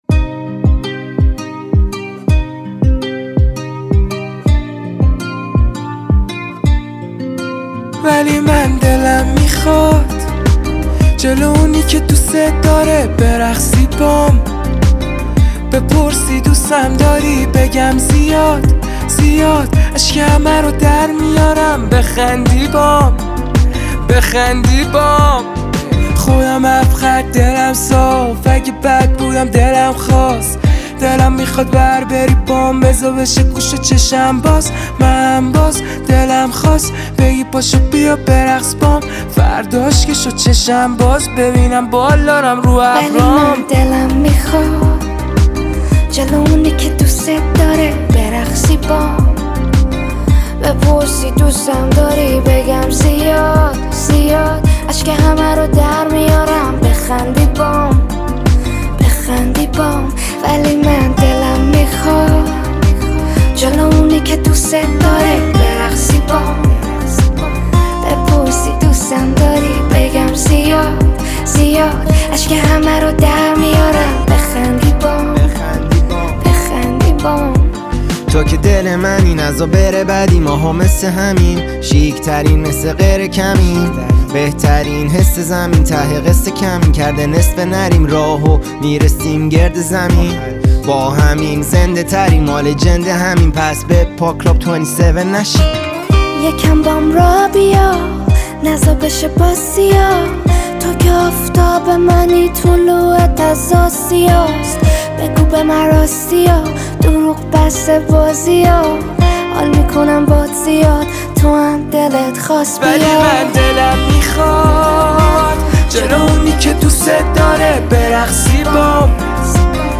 این قطعه پرانرژی